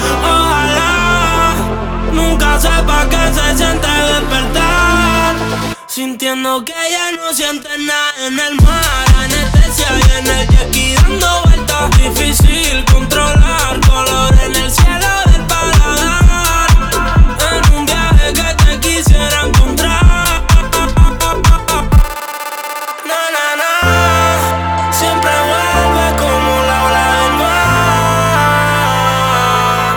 Жанр: Латиноамериканская музыка / Русские